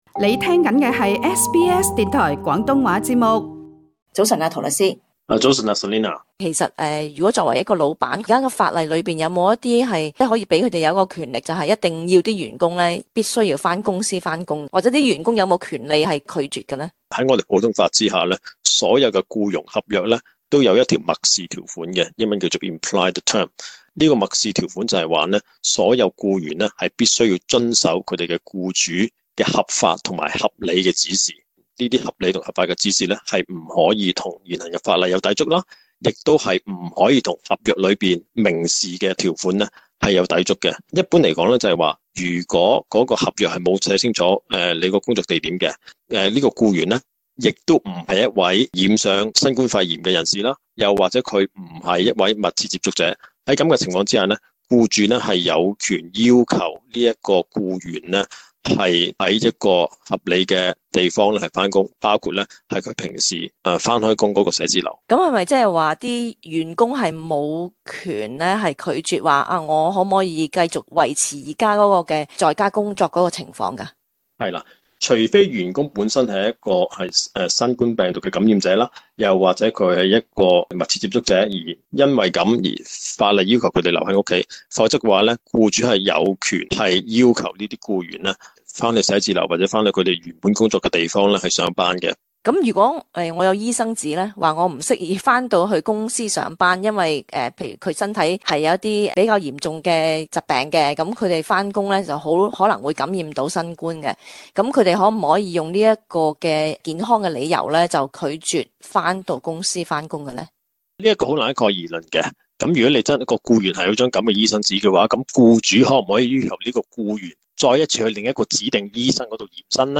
詳情請收聽這節訪問。